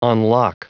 Prononciation du mot unlock en anglais (fichier audio)
Prononciation du mot : unlock